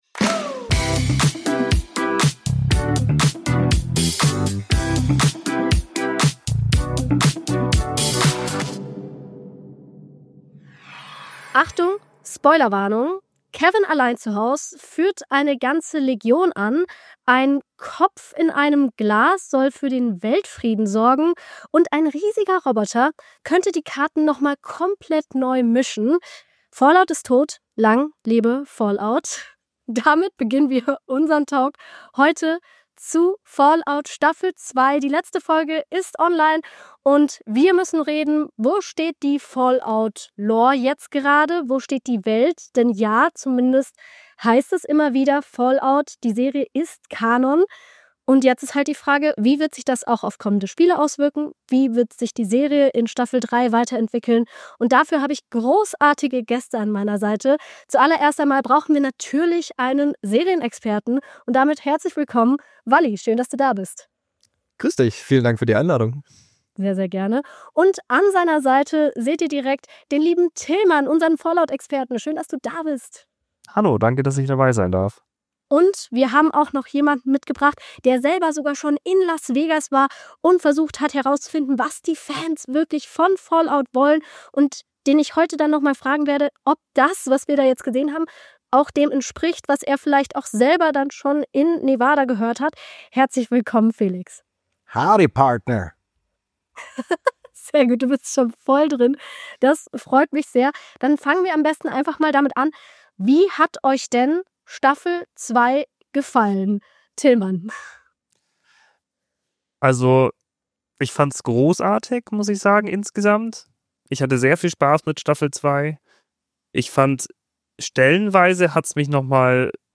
In diesem Talk analysieren wir die zweite Staffel und diskutieren, warum es jetzt nach Colorado geht.